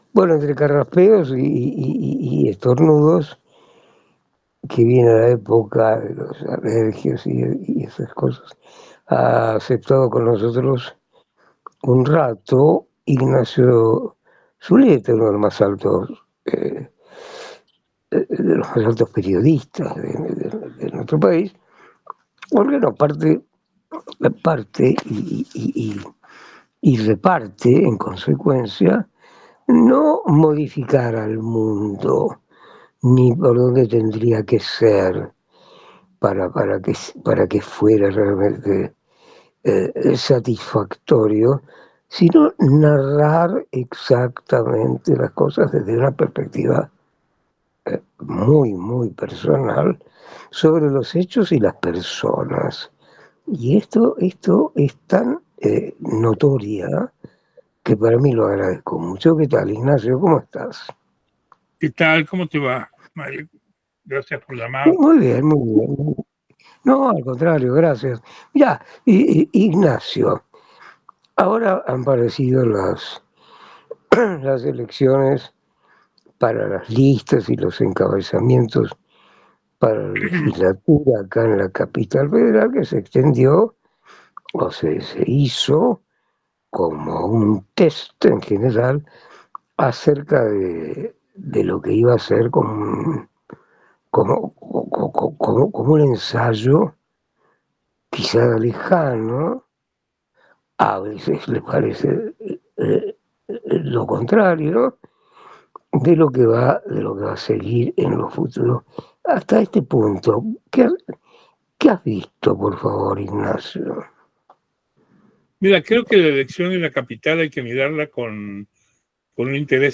Comparto un diálogo con Mario Mactas en el programa "El factor Mario", que se emite por radio Cultura, FM 97.9. conversamos sobre las elecciones legislativas del domingo en CABA y su proyección hacia las generales de octubre.